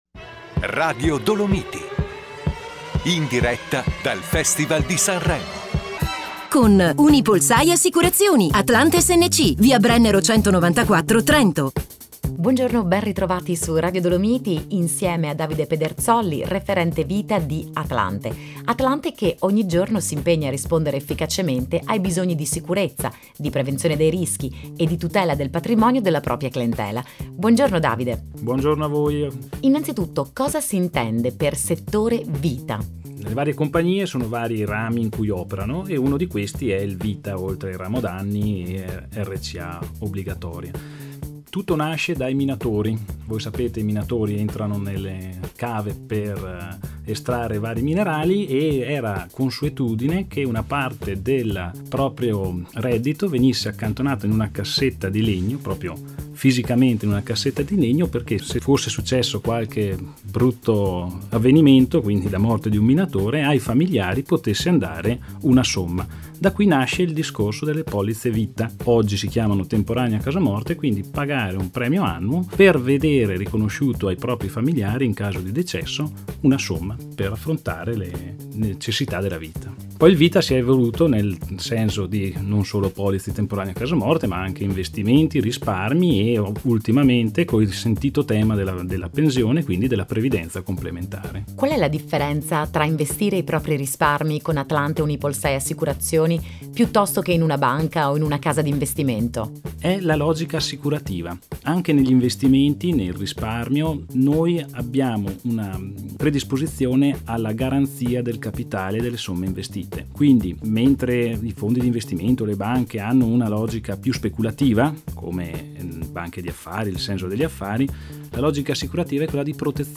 Ad inizio febbraio i nostri consulenti sono stati ospiti di Radio Dolomiti.